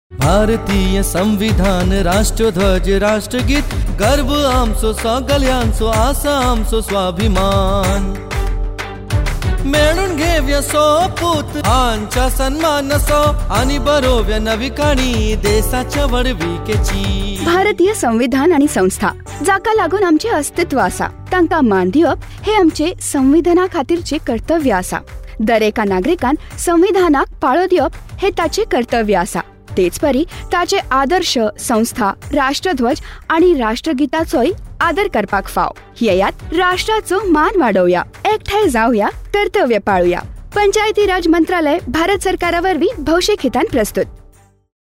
102 Fundamental Duty 1st Fundamental Duty Abide by the Constitution and respect National Flag and National Anthem Radio Jingle Konkani